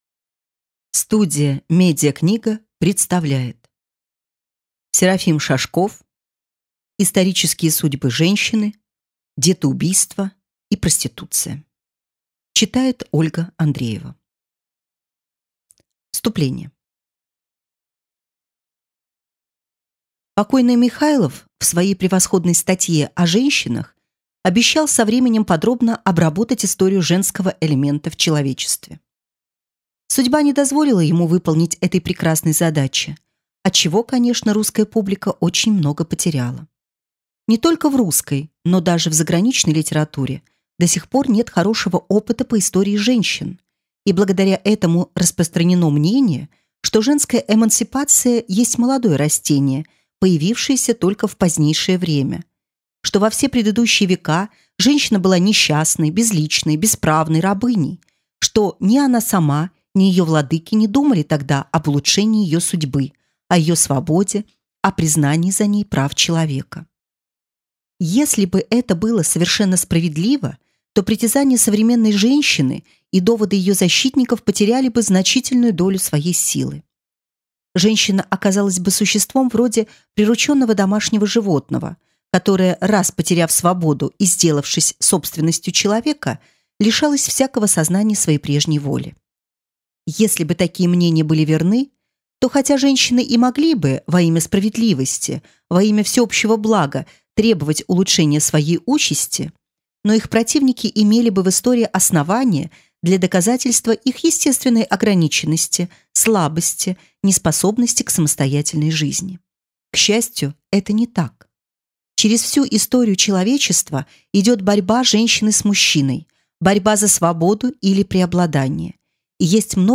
Аудиокнига Исторические судьбы женщин | Библиотека аудиокниг